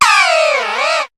Cri de Dodoala dans Pokémon HOME.